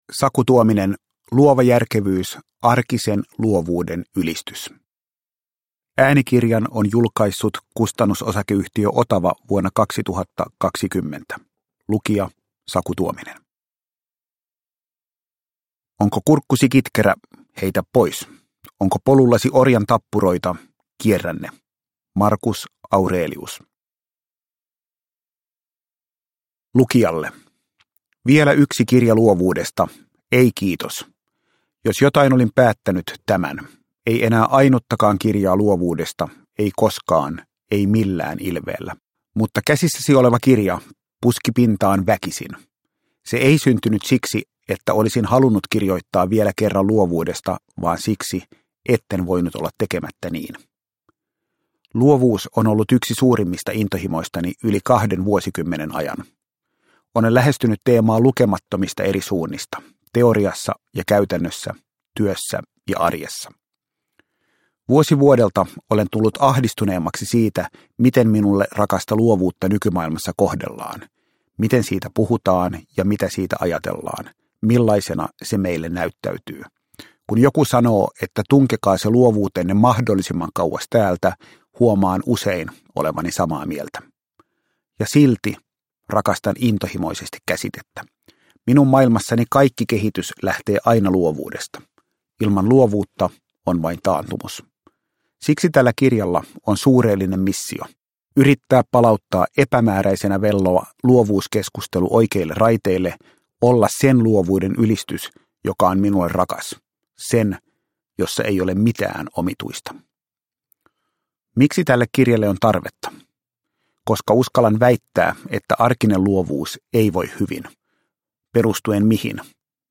Luova järkevyys – Ljudbok – Laddas ner